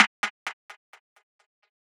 Perc 13.wav